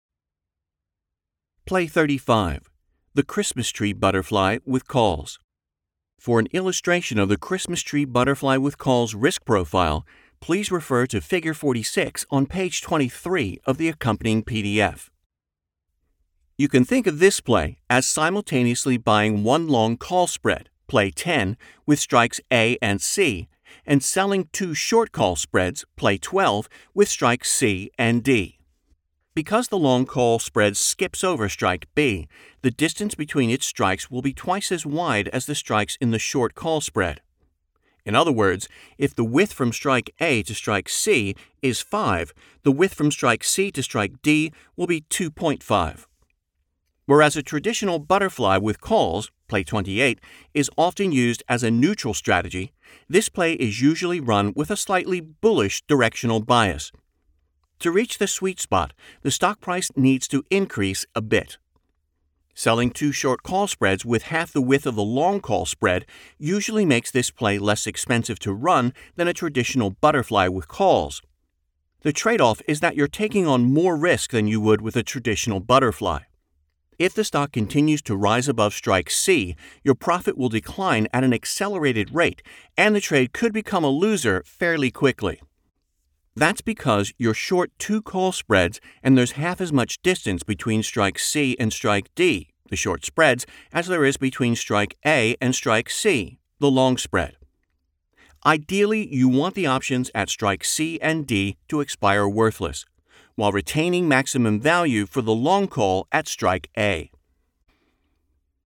Press Play below to hear an Exclusive Preview – Merry Christmas!